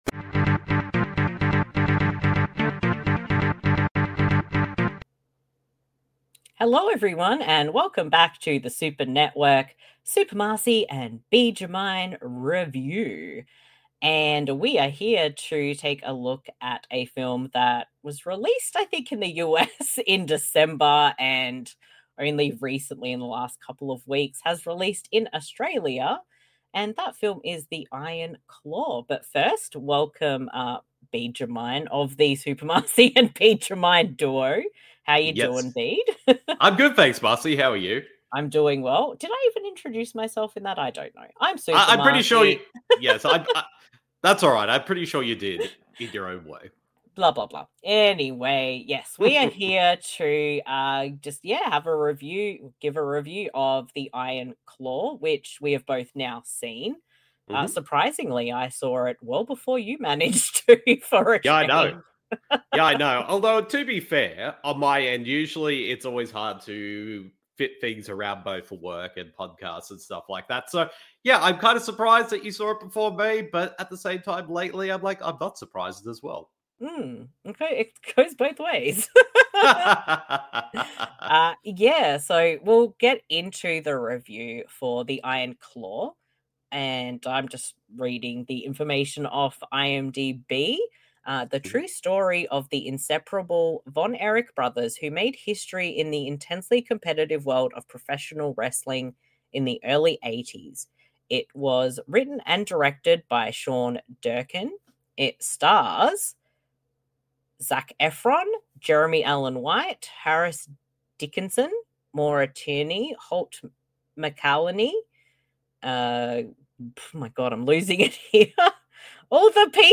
[Video/Audio Review] The Iron Claw (2023)